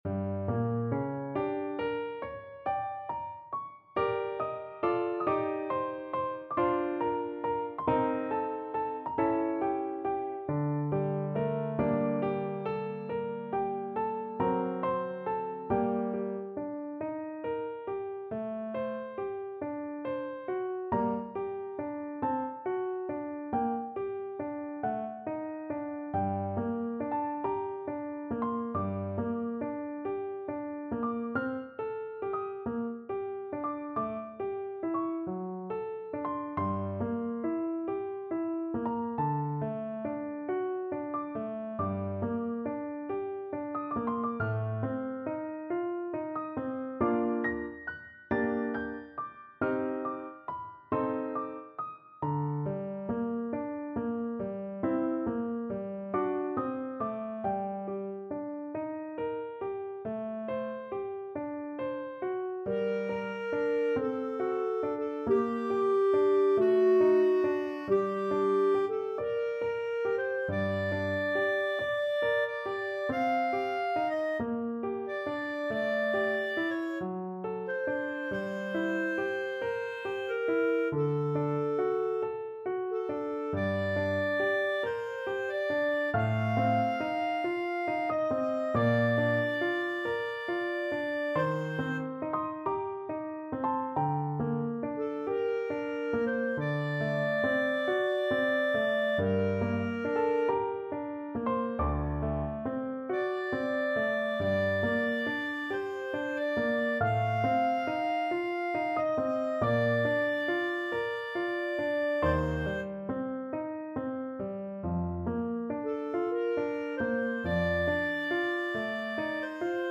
4/4 (View more 4/4 Music)
Slow =c.46
Classical (View more Classical Clarinet Music)